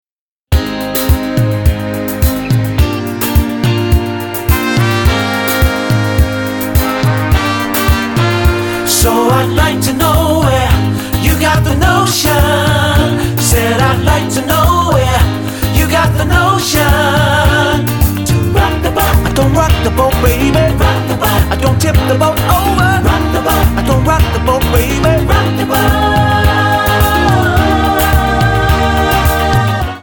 Tonart:Eb Multifile (kein Sofortdownload.
Die besten Playbacks Instrumentals und Karaoke Versionen .